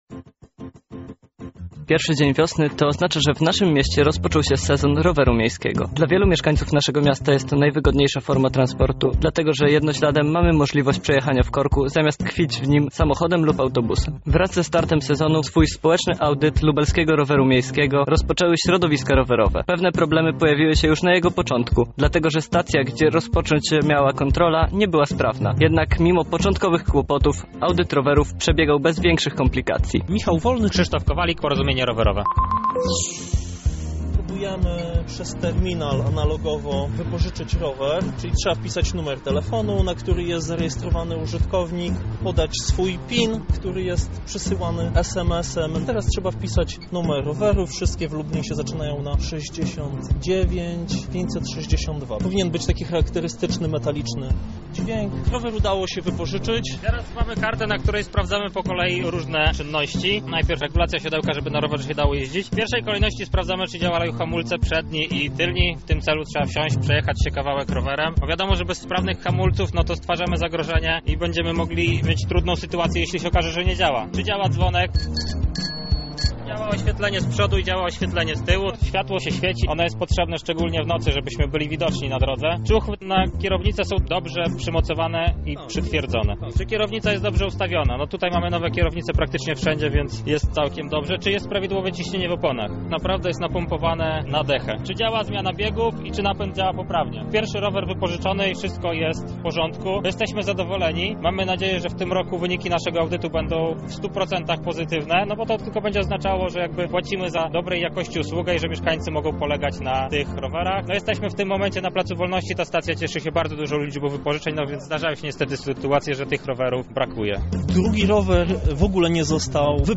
Więcej na temat początku nowego sezonu usłyszycie w materiale naszego reportera: